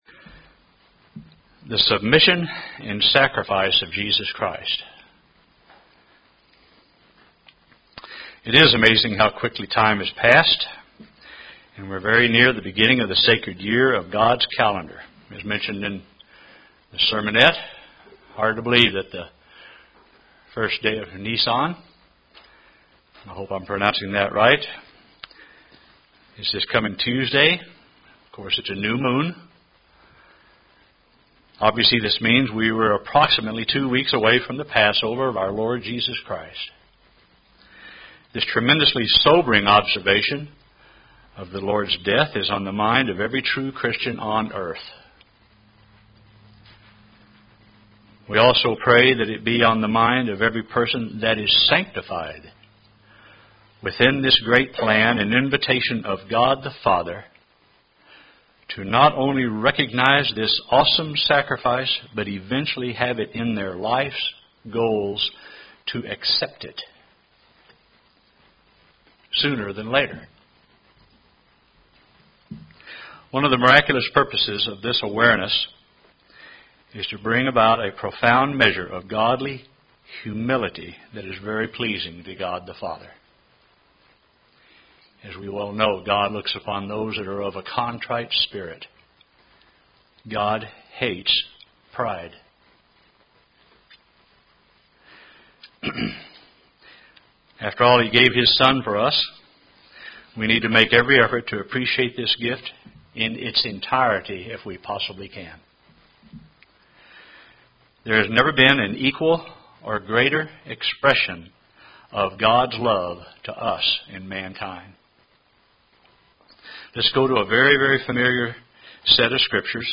Given in Oklahoma City, OK
UCG Sermon Studying the bible?